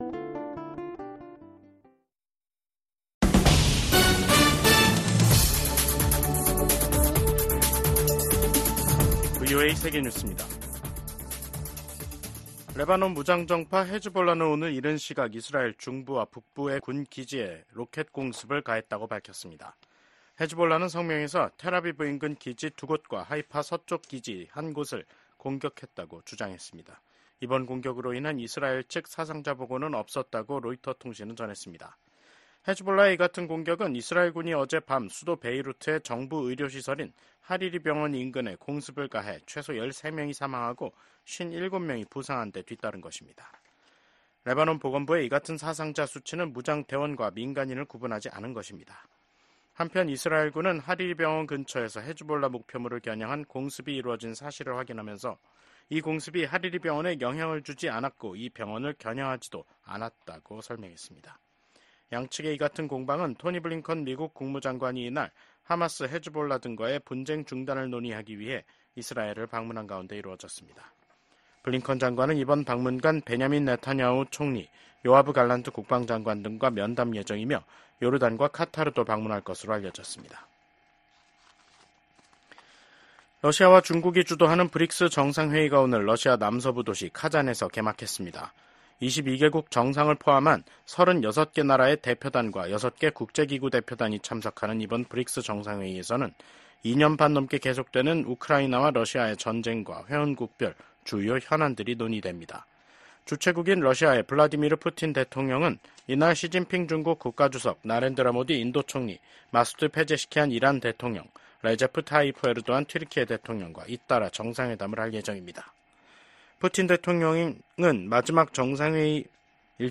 VOA 한국어 간판 뉴스 프로그램 '뉴스 투데이', 2024년 10월 22일 2부 방송입니다. 미국 정부가 북한의 러시아 파병은 러시아 대통령의 절박함과 고림감이 커지고 있다는 증거라고 지적했습니다. 군축과 국제안보 문제를 다루는 유엔 총회 제1위원회 회의에서 북한의 핵∙미사일 프로그램 개발에 대한 우려와 규탄이 연일 제기되고 있습니다.